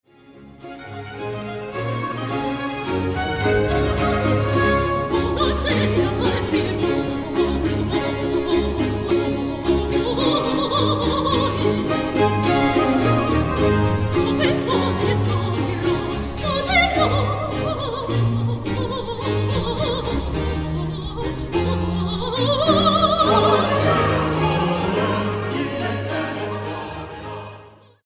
(Livemitschnitt)
Sopran
Vokalensemble der Akademie für Alte Musik HfK Bremen
Barockorchester HfK Bremen